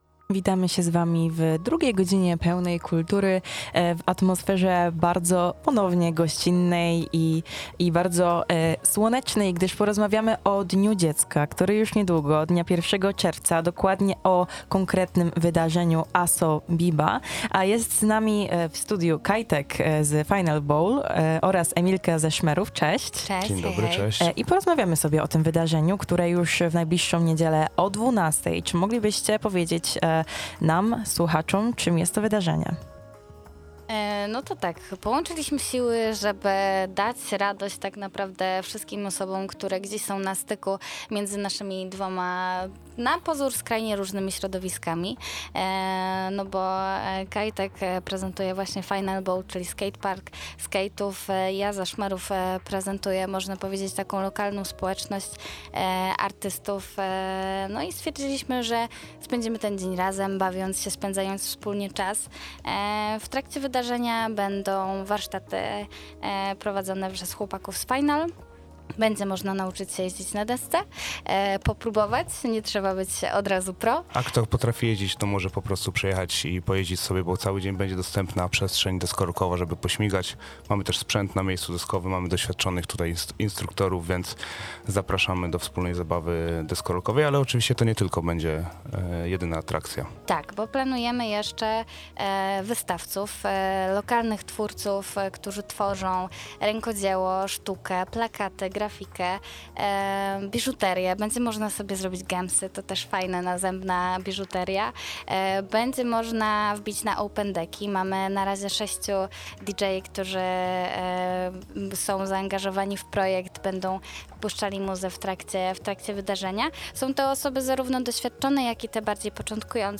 Posłuchaj ich rozmowy